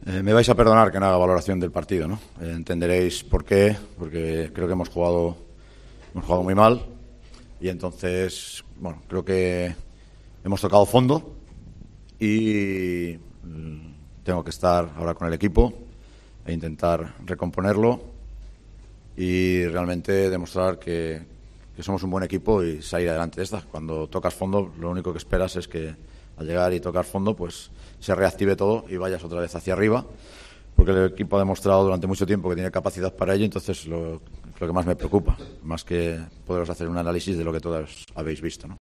El entrenador de Unicaja analizó la dolorosa derrota del equipo ante el Valencia.